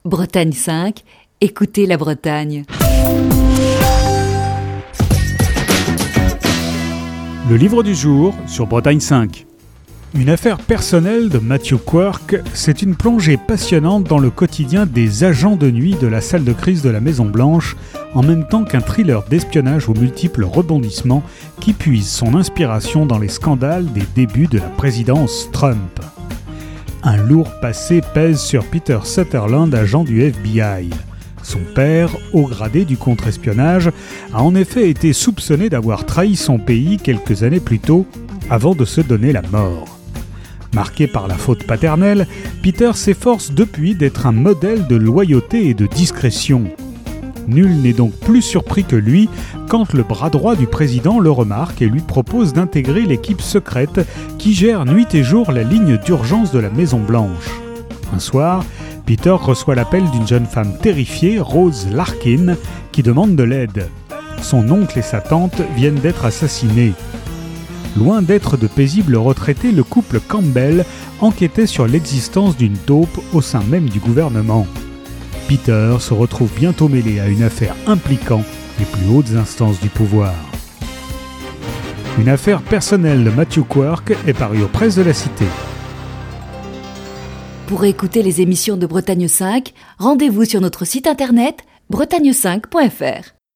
Chronique du 22 juin 2020.